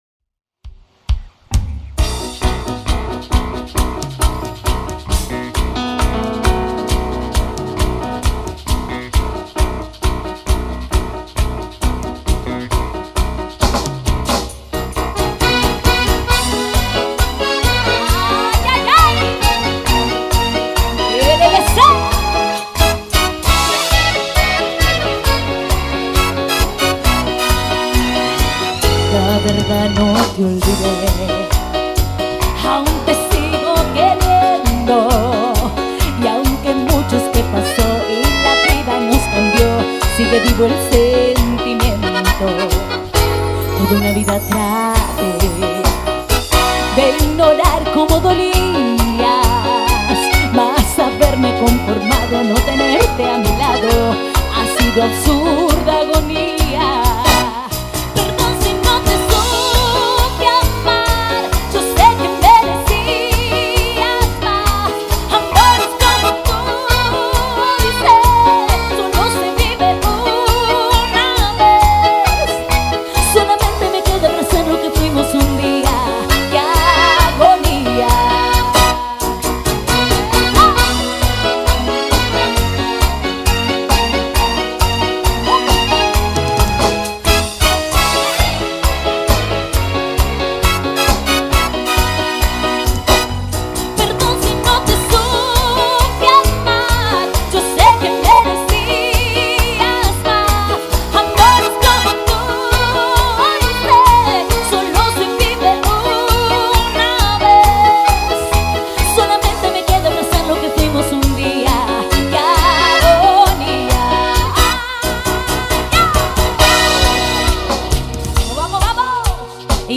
Carpeta: Cumbia y + mp3
En Vivo